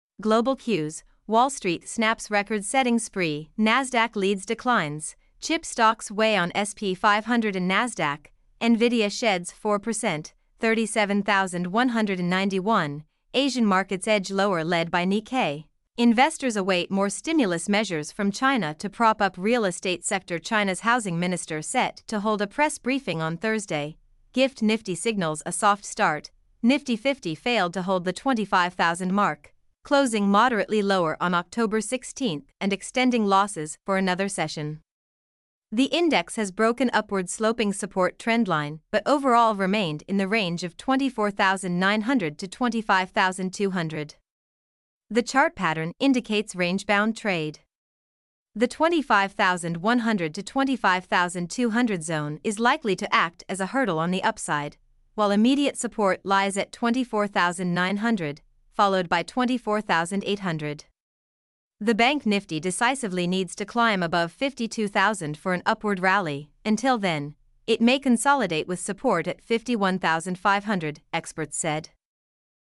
mp3-output-ttsfreedotcom-10.mp3